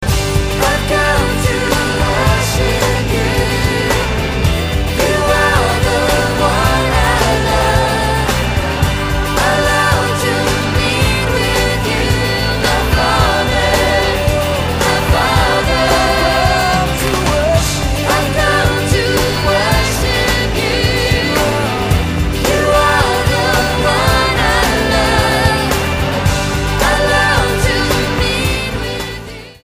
STYLE: Pop
Unlike a lot of contemporary live worship albums